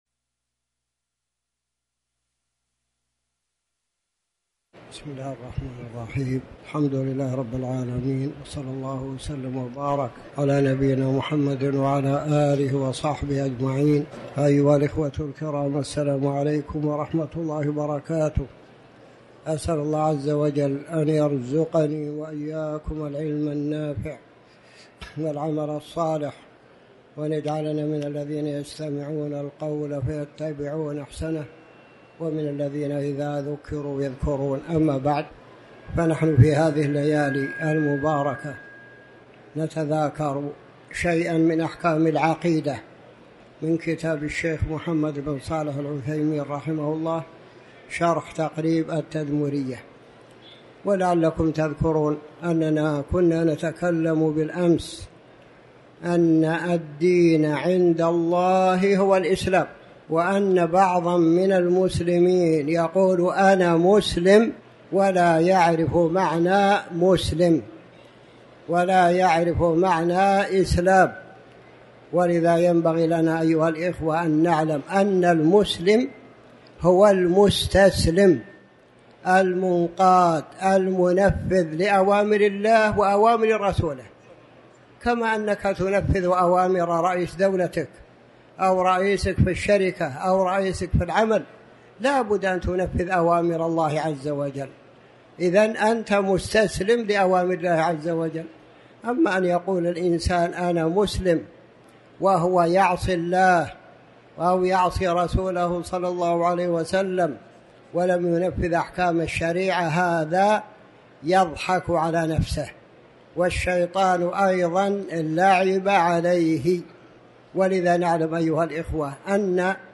تاريخ النشر ٢٣ ربيع الثاني ١٤٤٠ هـ المكان: المسجد الحرام الشيخ